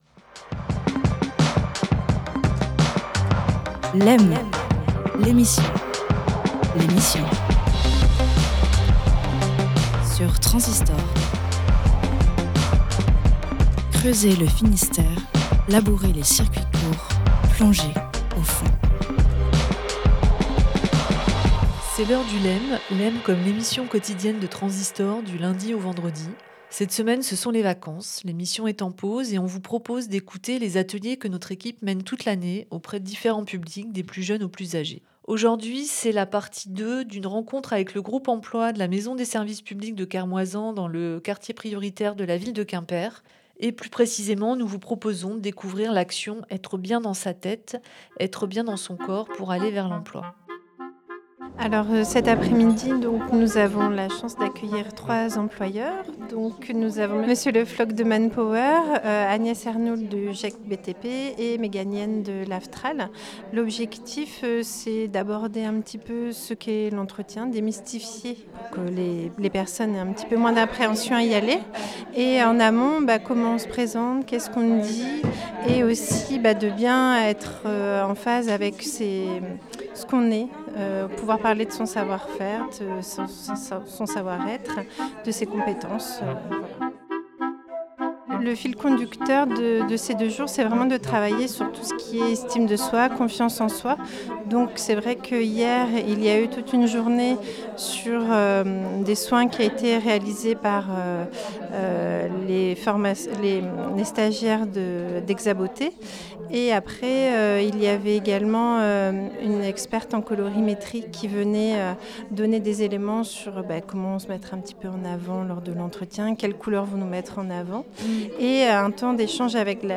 Il s'agit de la dernière demie journée consacrée à l'entretien de recrutement, réalisée dans la salle des mariages de la maison des services publics de Kermoysan.